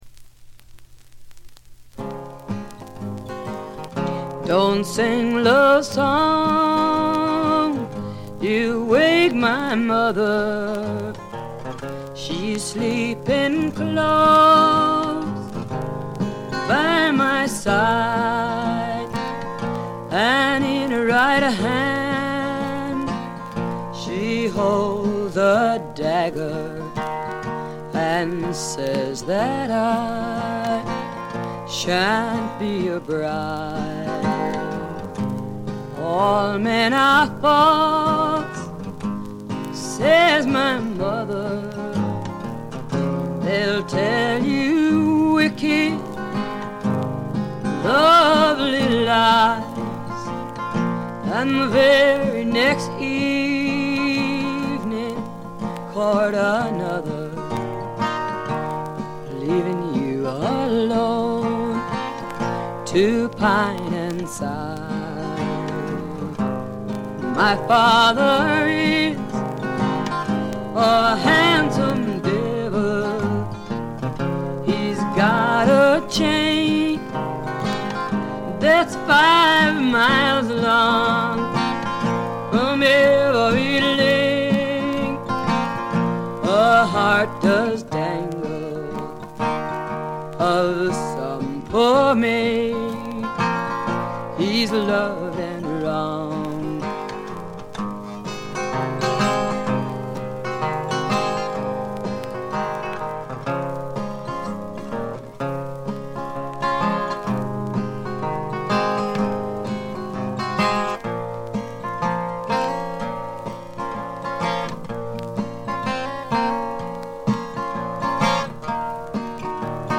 バックグラウンドノイズ、チリプチは常時大きめに出ます。
存在感抜群のアルト・ヴォイスが彼女の最大の武器でしょう。
試聴曲は現品からの取り込み音源です。